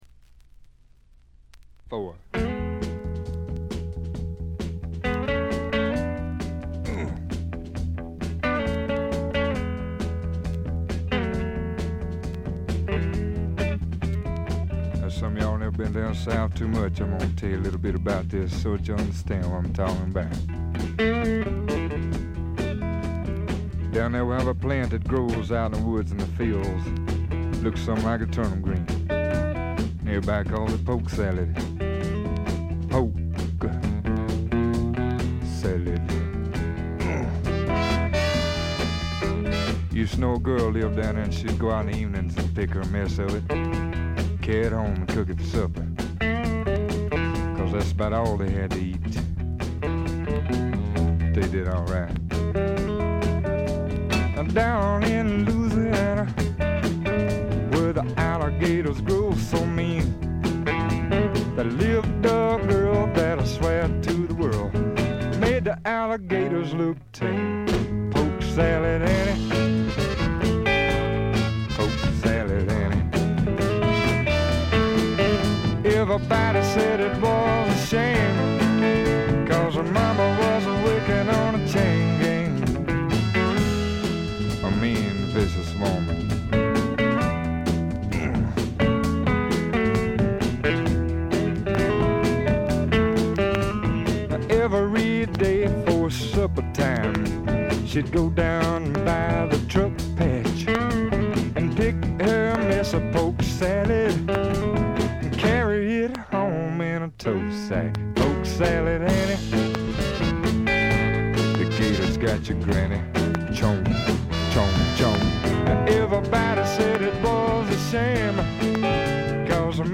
チリプチ、プツ音多め大きめ。
スワンプ基本！
試聴曲は現品からの取り込み音源です。
Guitar, Harmonica